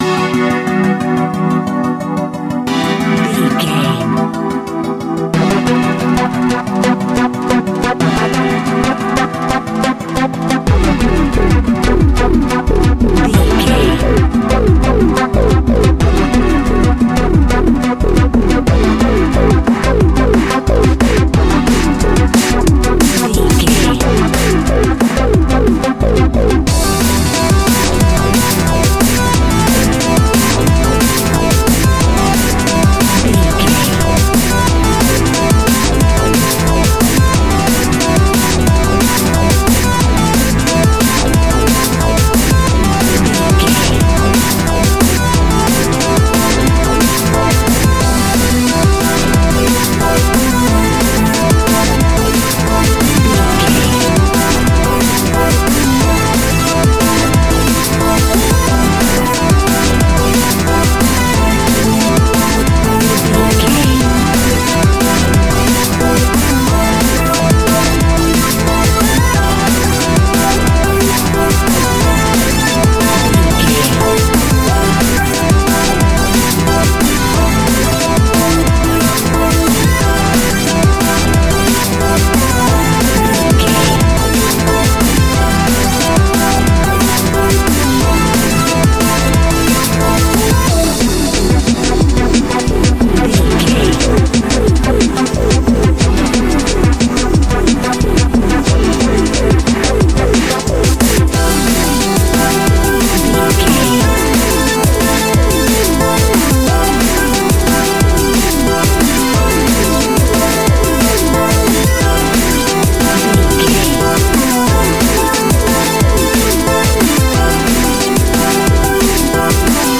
Drum and Bass Futuristic Style.
Epic / Action
Fast paced
Aeolian/Minor
Fast
dark
aggressive
synthesiser
drum machine
sub bass
synth leads